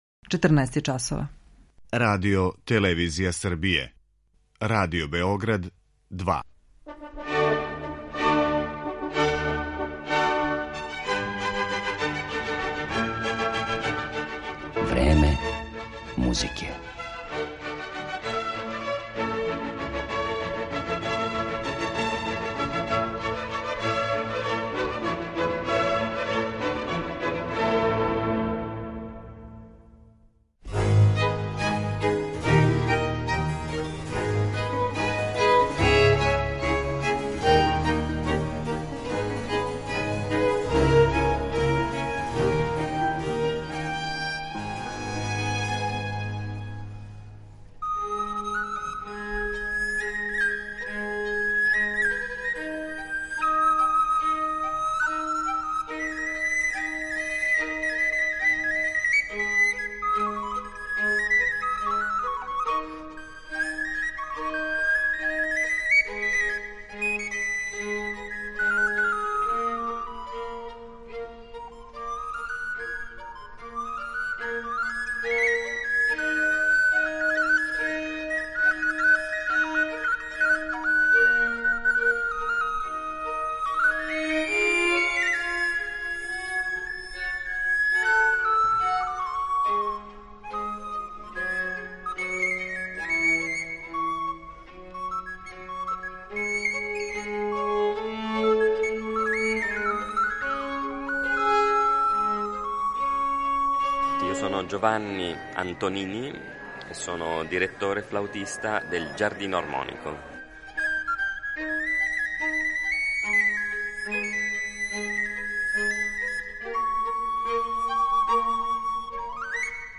Једном од најзанимљивијих барокних ансамбала у Европи и саставу који је неколико пута гостовао и код нас ‒ Il Giardino Armonico ‒ посвећена је данашња емисија у којој ћете моћи да чујете и интервју са његовим руководиоцем и солистом на бројним врстама старе флауте Ђованијем Антонинијем
Ови изврсни музичари изводиће дела Тарквина Мерула, Антонија Вивалдија, Георга Филипа Телемана и Јохана Хајнриха Шмелцера.